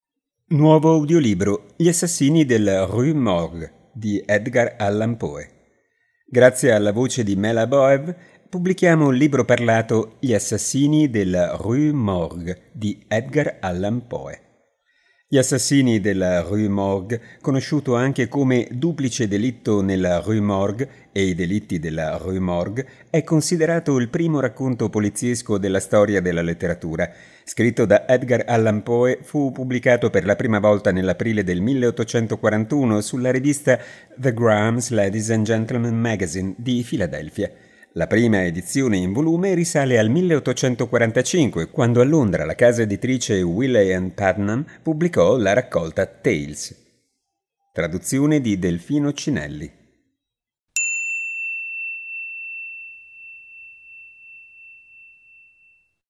Nuovo audiolibro. “Gli assassinii della Rue Morgue” di Edgar Allan Poe | Pagina Tre